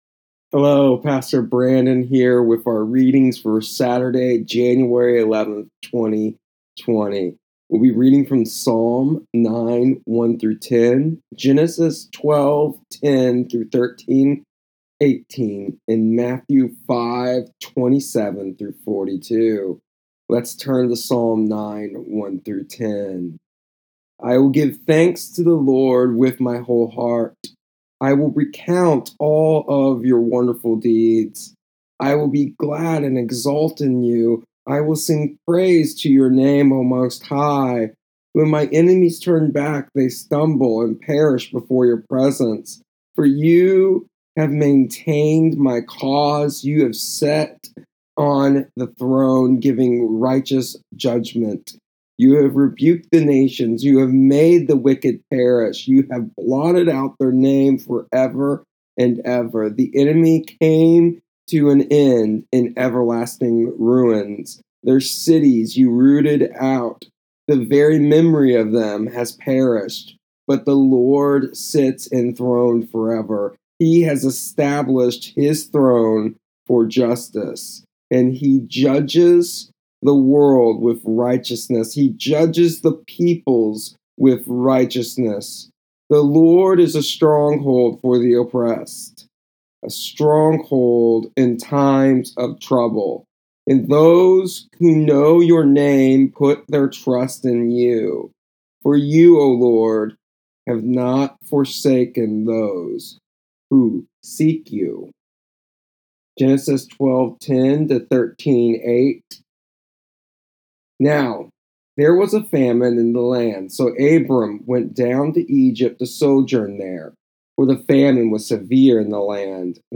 Here is the audio version and daily devotional for, January 11th, 2020, of our daily reading plan.